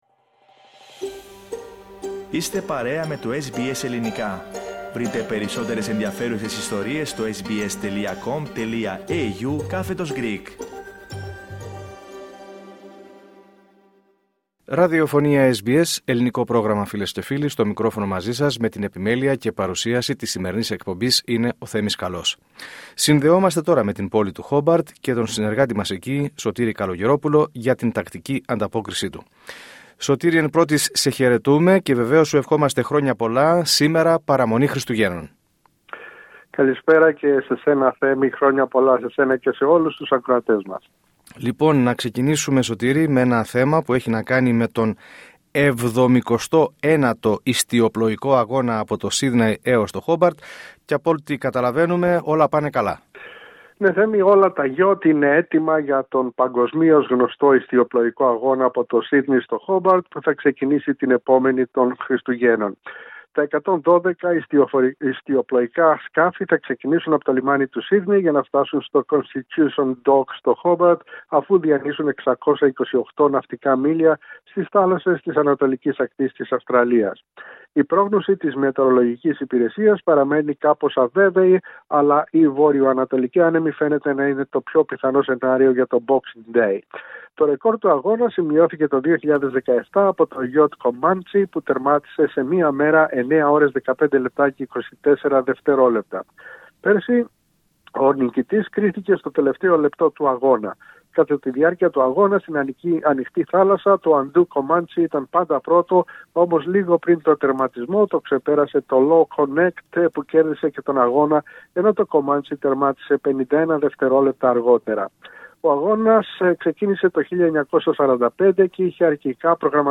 Ακούστε την εβδομαδιαία ανταπόκριση από την Τασμανία